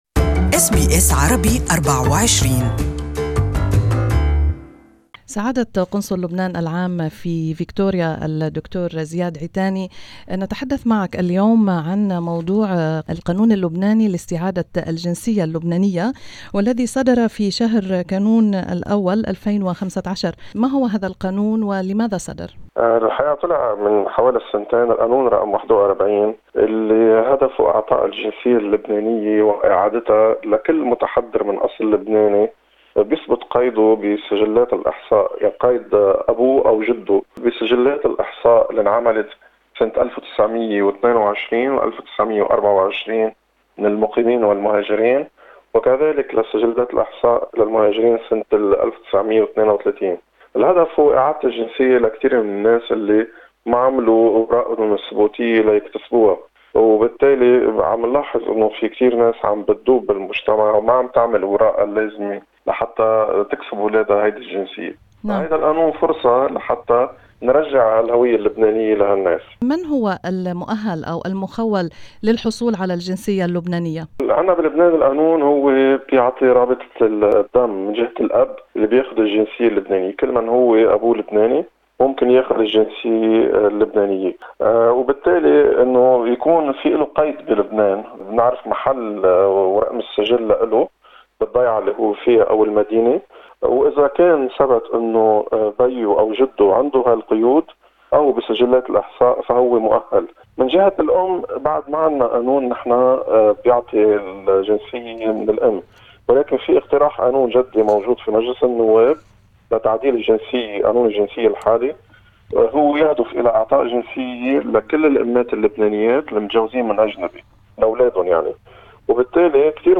Consul General of Lebanon in Victoria Dr Ziad Itani explains the benefits and procedure of reclaiming the Lebanese citizenship in this interview in Arabic.